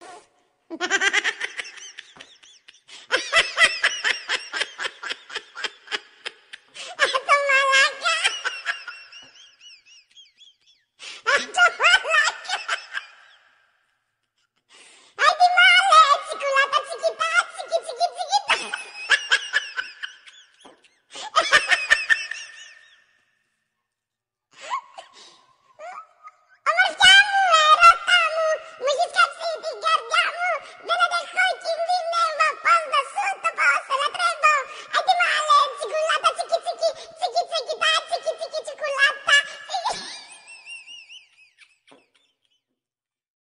Suara Ketawa anak Kecil
Kategori: Suara ketawa
Keterangan: Suara ketawa anak kecil yang menggemaskan, sound effect tawa bayi yang lucu dan ngakak, cocok untuk menambah keceriaan dalam video Anda.
suara-ketawa-anak-kecil-id-www_tiengdong_com.mp3